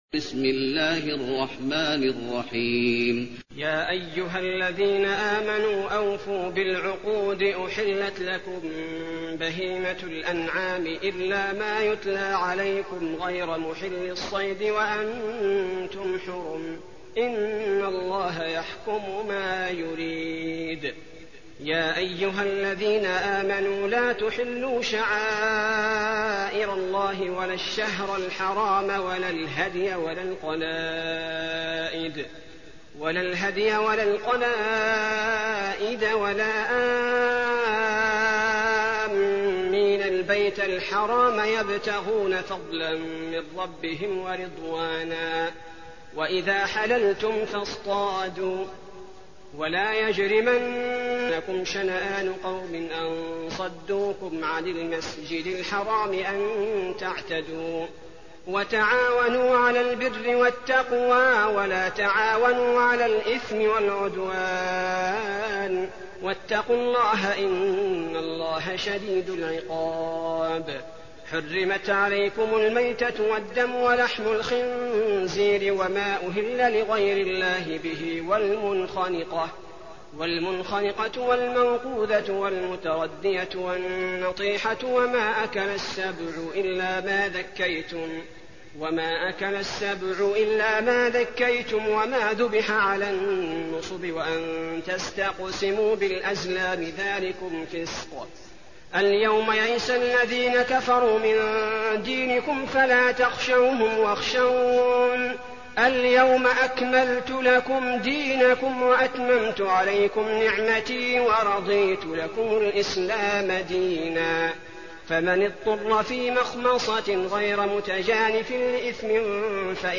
المكان: المسجد النبوي المائدة